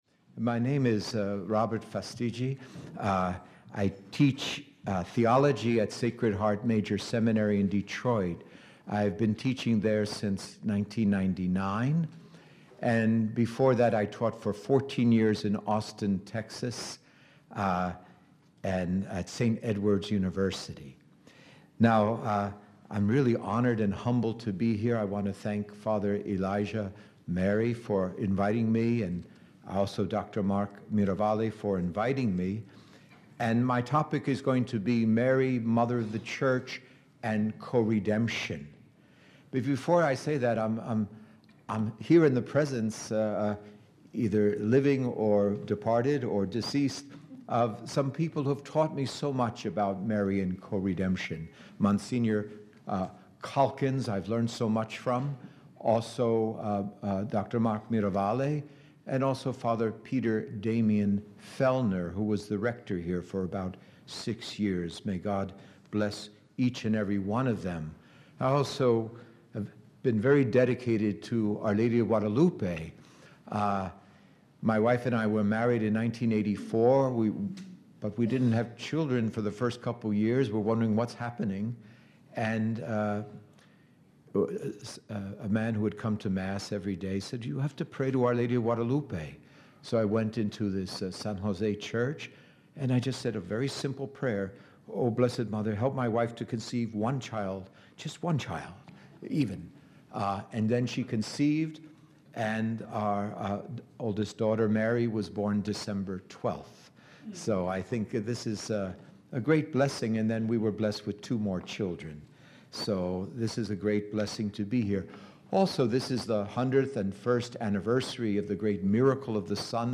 at the Marian Coredemption symposium at the Shrine of Our Lady of Guadalupe, La Crosse, WI in 2018